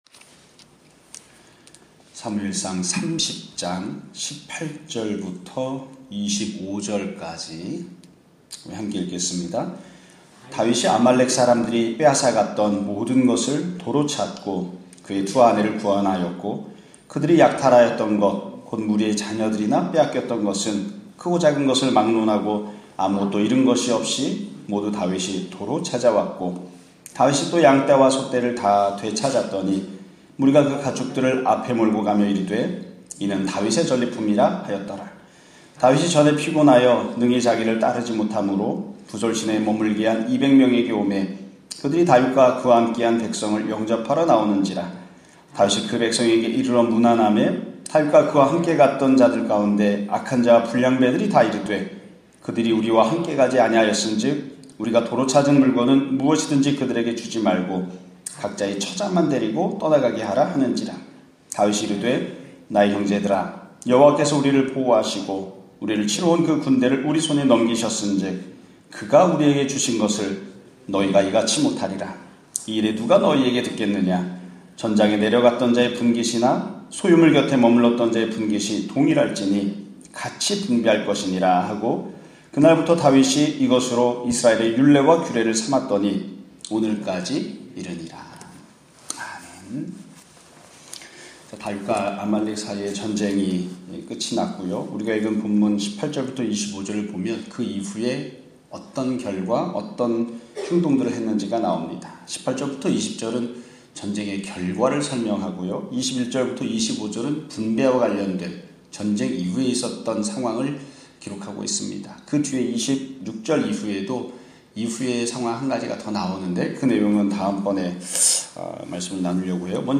2021년 12월 17일(금요일) <아침예배> 설교입니다.